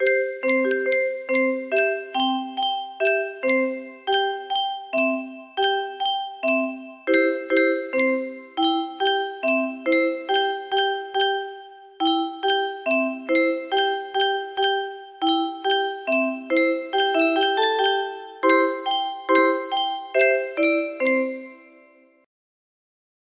곰세마리 music box melody